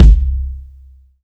KICK.8.NEPT.wav